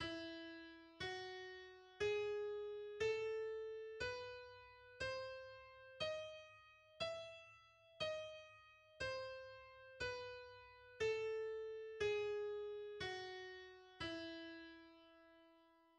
The E harmonic major and melodic major scales are: